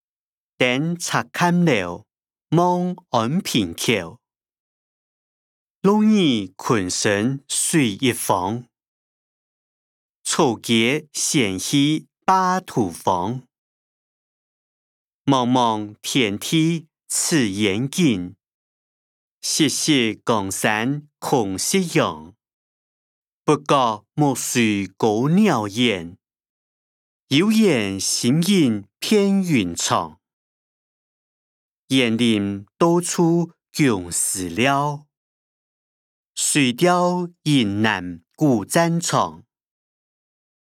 古典詩-登赤嵌樓望安平口音檔(四縣腔)